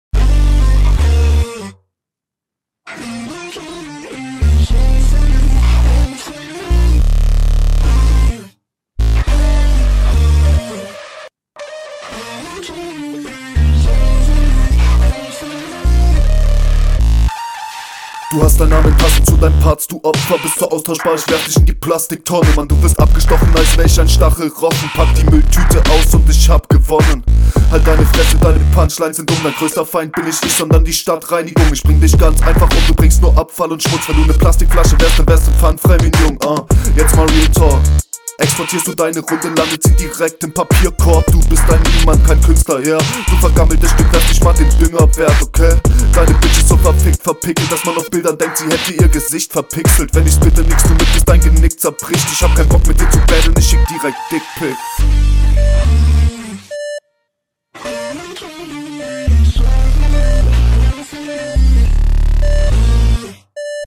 Flow und Sound auch good aber kommt im Vergleich leider nicht ganz ran.